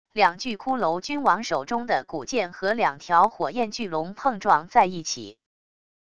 两具骷髅君王手中的骨剑和两条火焰巨龙碰撞在一起wav音频